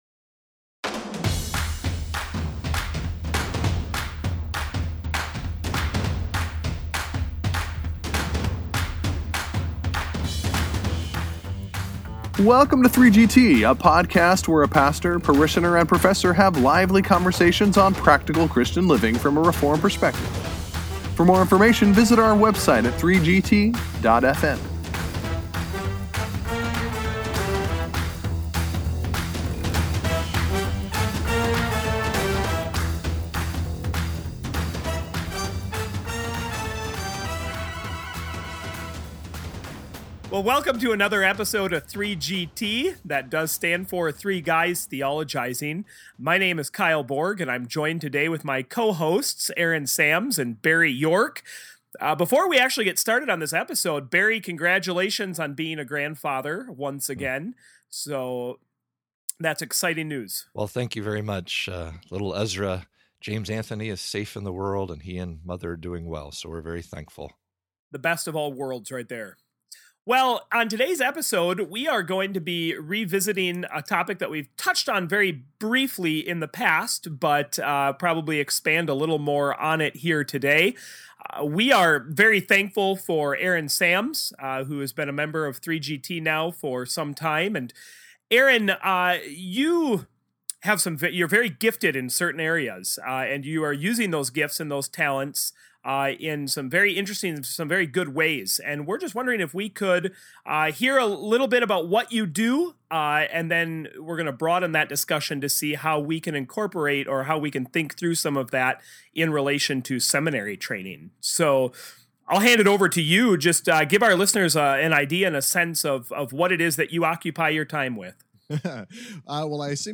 In this episode we interview one of our own
Join us for this fast and fun-filled talk